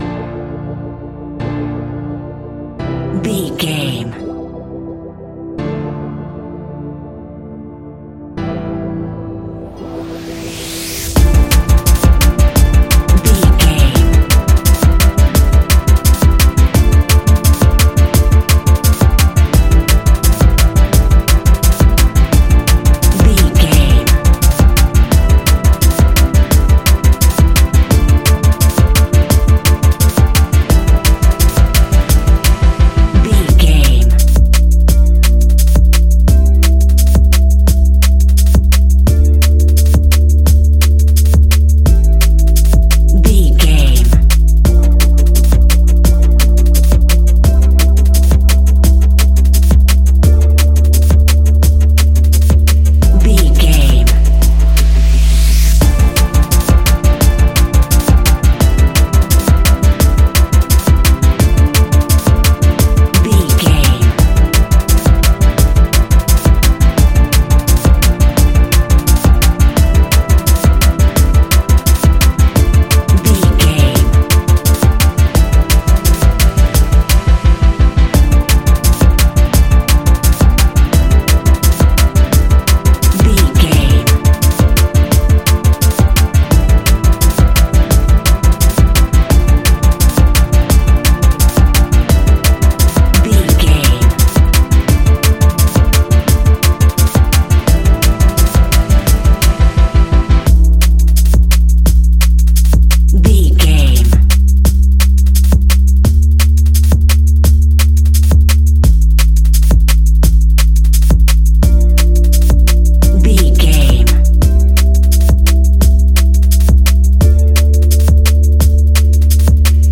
Ionian/Major
A♯
electronic
techno
trance
synths
synthwave
instrumentals